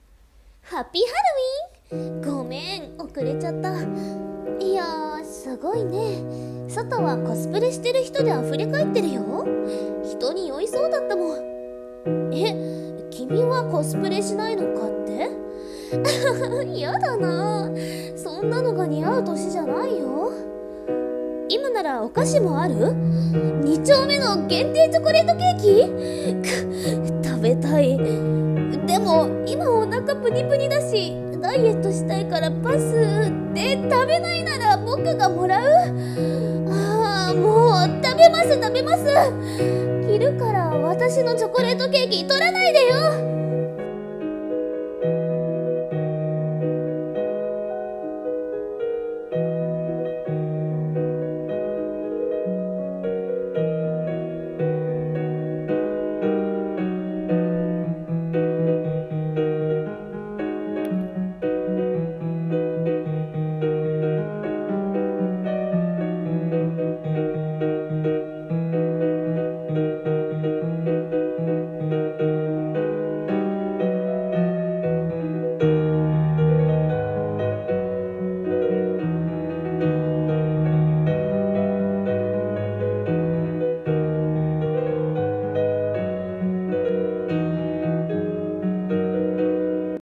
〖声劇〗ハロウィン